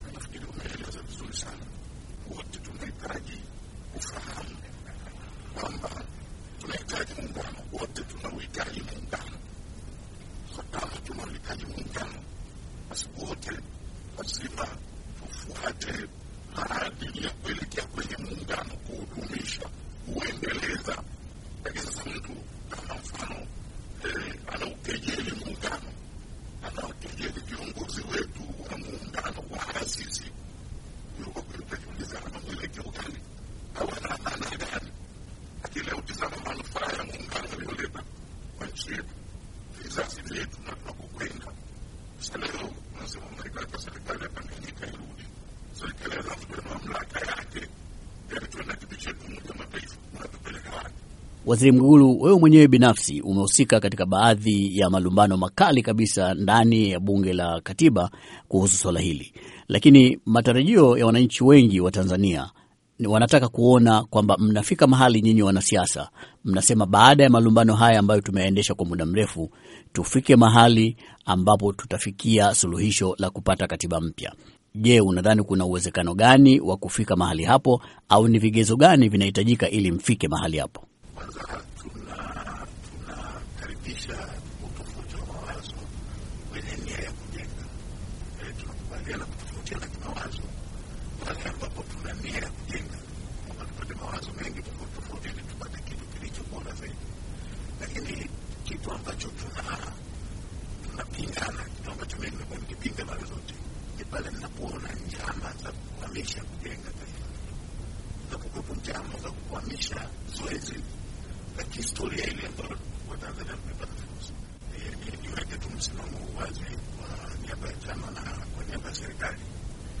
Mahojiano na Mwinyihaji Makame na Mwigulu Nchemba